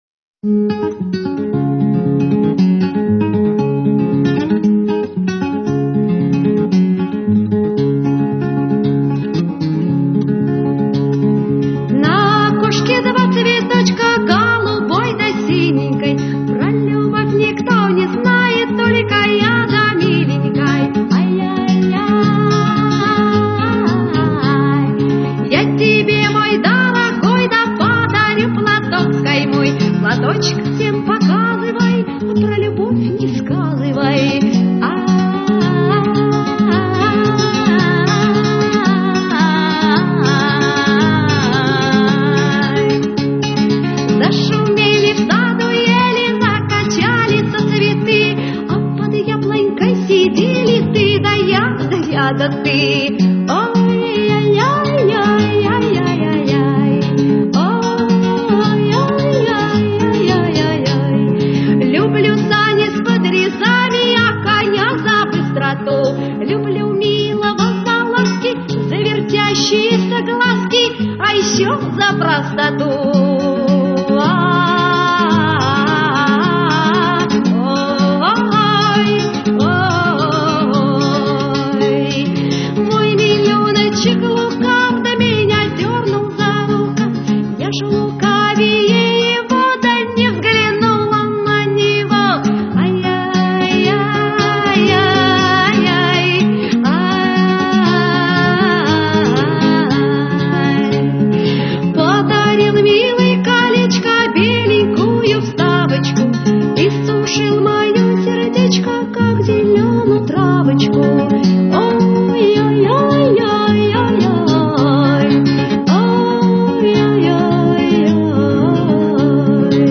Архив mp3 / Слово и музыка / Авторская песня / Жанна Бичевская /
Старые русские народные деревенские песни и баллады, часть 2